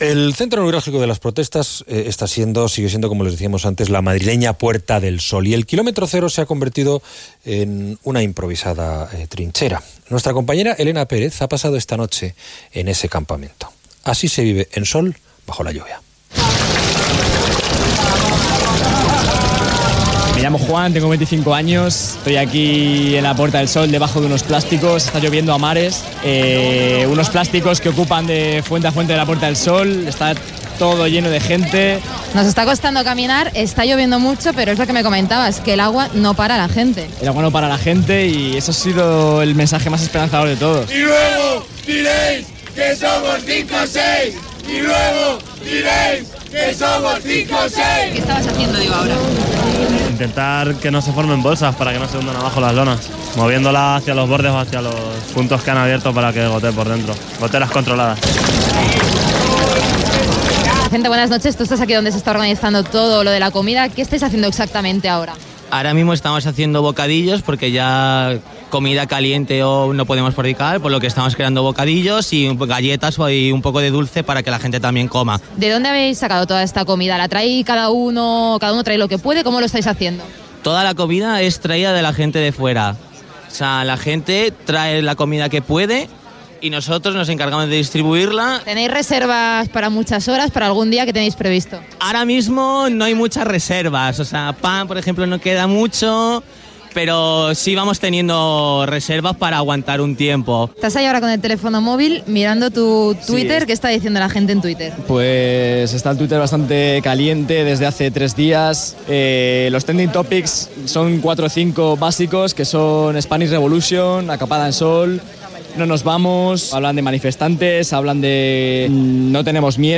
Reportatge enregistrat a l'acampada de la Puerta del Sol de Madrid durant l'acampada del 15 de maig del moviment dels indignats, conegut posteriorment com Moviment 15-M. Indicatiu del programa
Informatiu